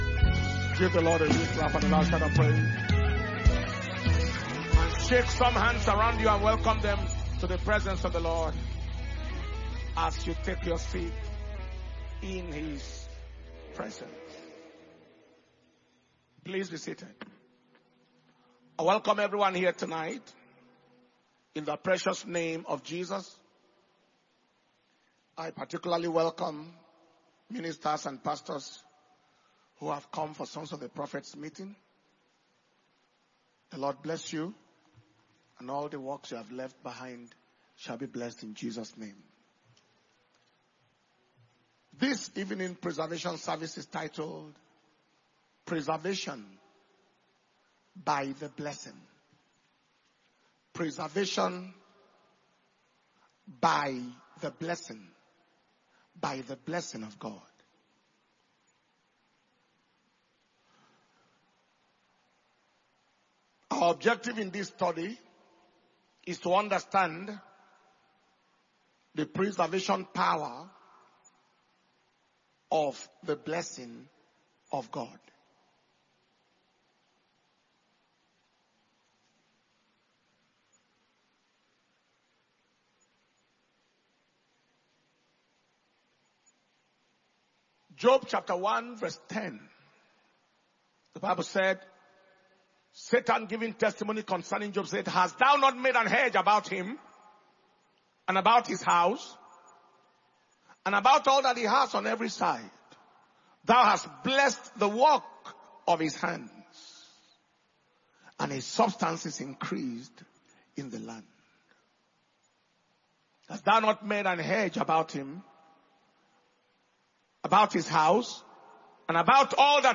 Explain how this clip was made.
March 2025 Preservation and Power Communion Service